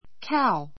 cow 小 A1 káu カ ウ 名詞 雌牛 めうし , 乳牛; 牛 ⦣ ふつう子を産んだ3歳 さい 以上の雌牛をいうが, 雌雄 しゆう の別なく「牛」全般 ぜんぱん を指すのにも使う. keep [have] cows keep [ have ] cows 牛を飼う She is milking a cow.